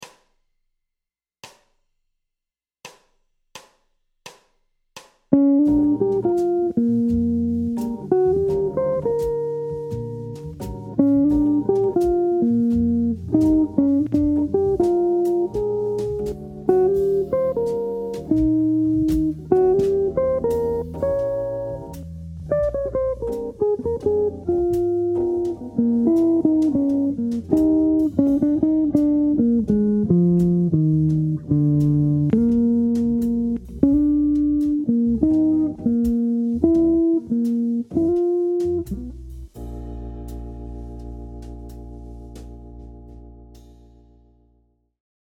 Quand simplicité rime avec facilité, un trait sur le degré I7 faisant ressortir la Sixte.
Exemple d’emploi du Lick dans un Blues en Bb
Blues-Lick-25-1.mp3